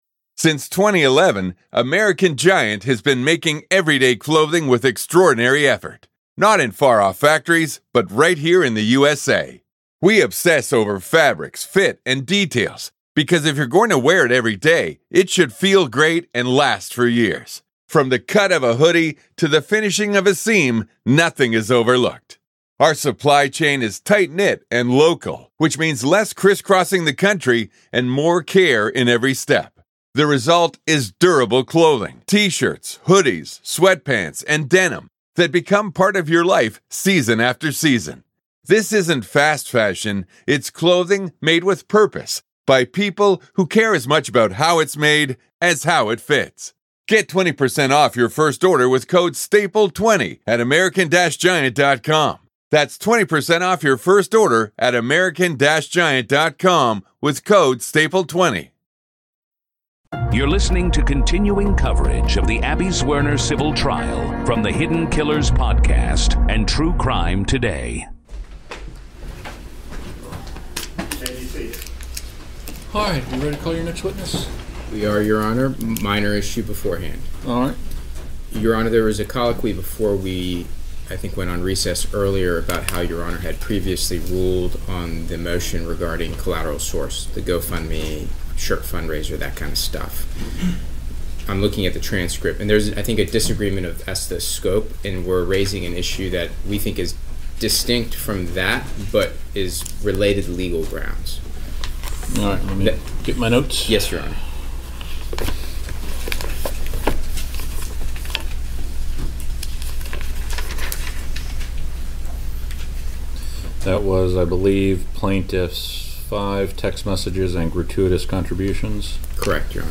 Each day’s proceedings bring new testimony, evidence, and revelations about what happened inside Richneck Elementary School on January 6, 2023 — and the administrative failures that followed. You’ll hear unfiltered courtroom audio, direct from the trial
Hidden Killers brings you the voices, the arguments, and the raw sound of justice in progress — as a jury decides whether silence and inaction inside a public school can rise to the level of legal accountability.